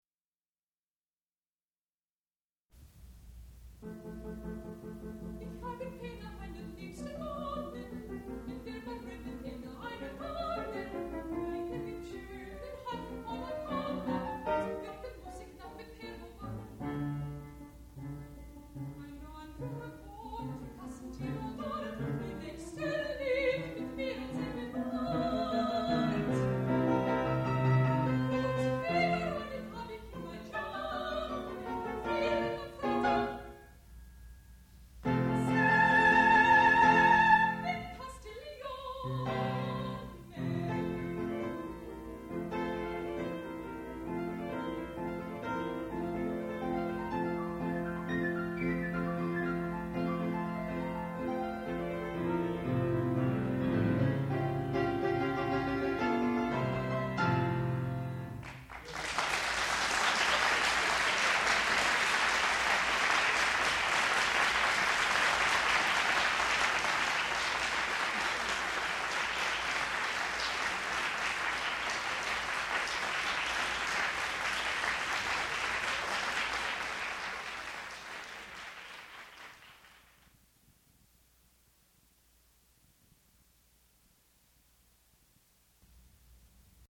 sound recording-musical
classical music
piano
Graduate Recital
mezzo-soprano